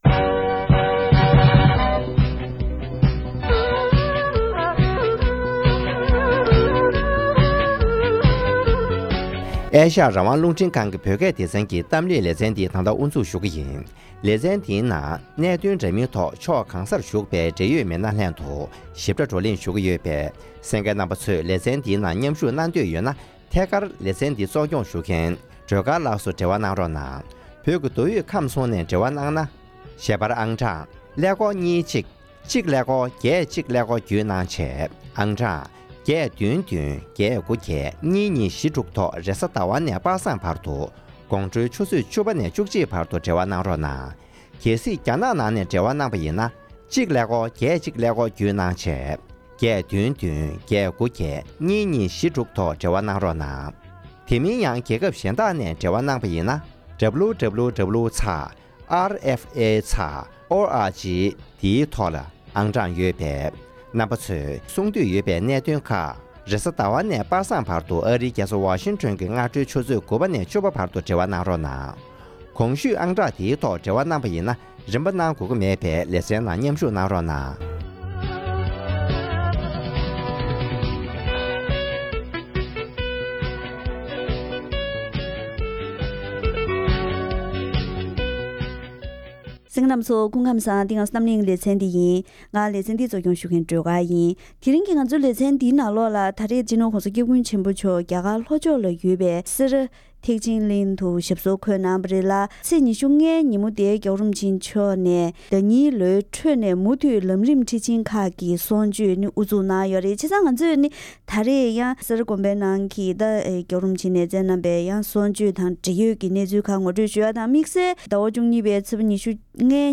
༄༅༎ཐེངས་འདིའི་གཏམ་གླེང་གི་ལེ་ཚན་ནང་དུ། སྤྱི་ནོར་༧གོང་ས་༸སྐྱབས་མགོན་ཆེན་པོ་མཆོག་གིས་རྒྱ་གར་ལྷོ་ཕྱོགས་སེ་ར་དགོན་པའི་ནང་གསུང་ཆོས་བསྩལ་སྐབས།